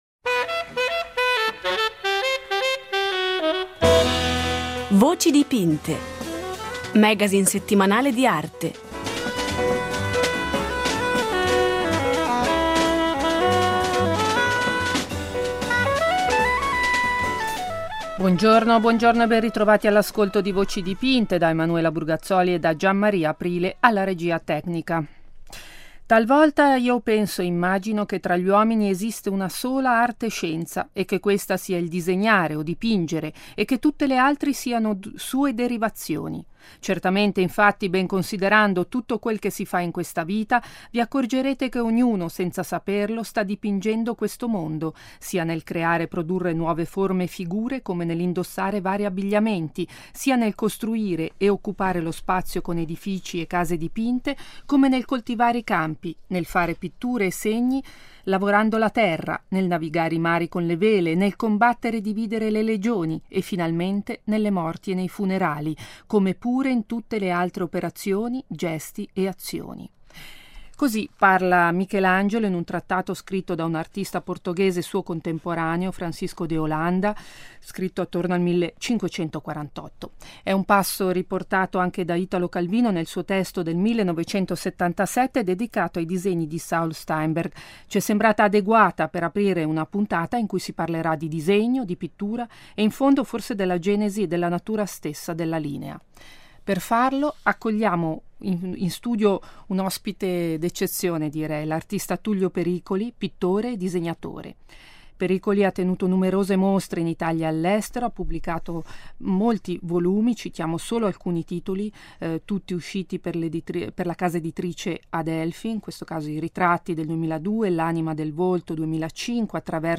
Tullio Pericoli è ospite di “Voci dipinte”, domenica alle 10:35 su Rete Due.